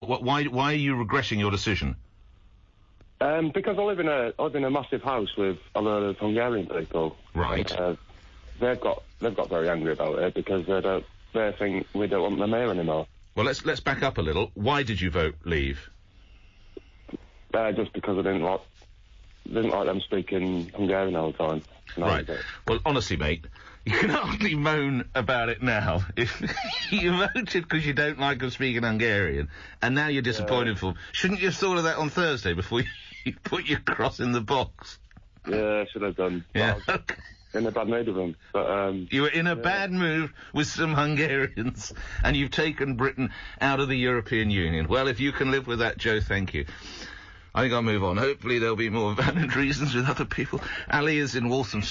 LBC Caller Says He Regrets Voting Leave Because His Housemates Speak Hungarian